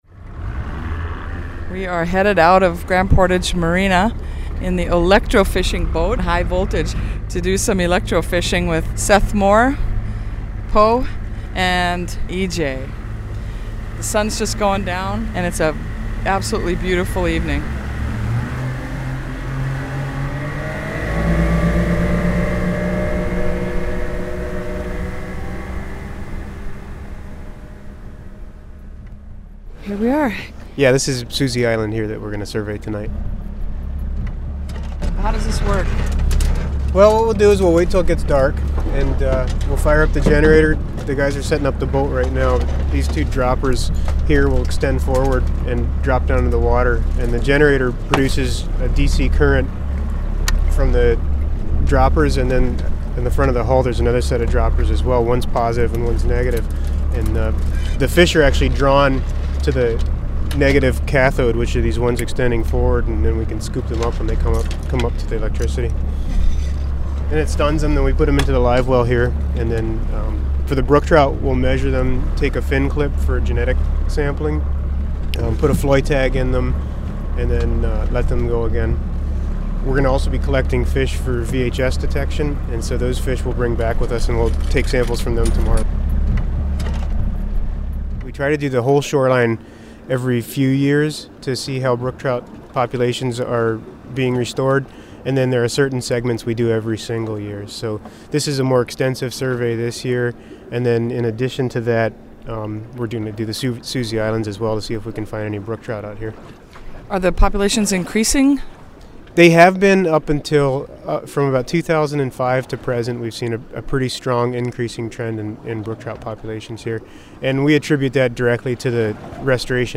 on an electrofishing survey around the coast of Susie Island, on Lake Superior.